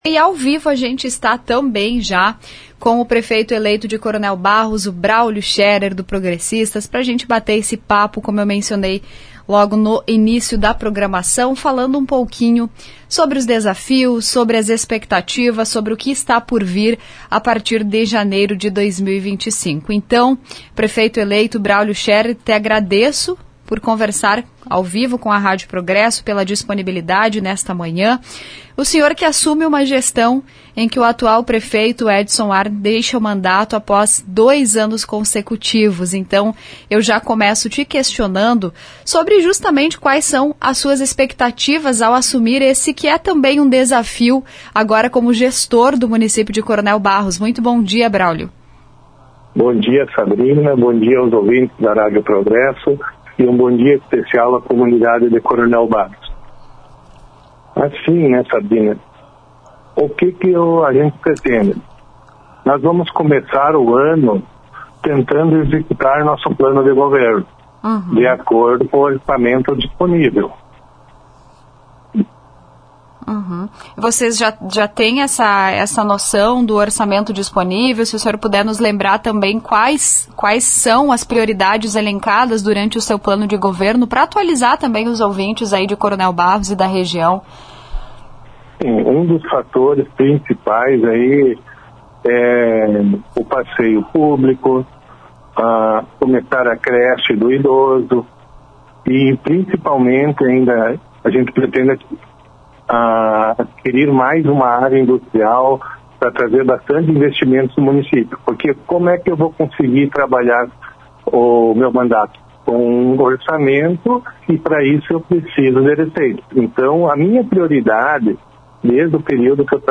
Em entrevista à Rádio Progresso hoje (18), o prefeito eleito de Coronel Barros, Bráulio Scherer (Progressistas), disse que continuar atraindo investimentos de novas empresas no município será uma das prioridades de sua gestão.
Entrevista-Braulio-Scherer.mp3